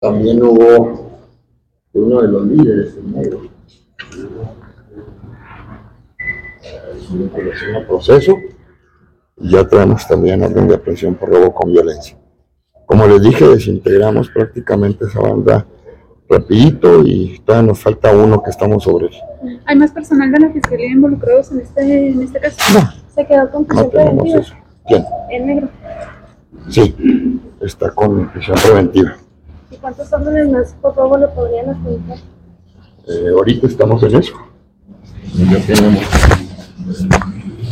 AUDIO: CÉSAR JAÚREGUI MORENO, FISCAL GENERAL DEL ESTADO (FGE)
Chihuahua, Chih.- Previo a la semanal Mesa de «Mesa de Seguridad para la Construcción de la Paz», que se realiza cada lunes -inicio de semana laboral-; el fiscal General del Estado, César Jauregui Moreno, se negó dar declaraciones iniciales y, se limitó a contestar los cuestionamientos de la prensa mientras se dirigía a su reunión.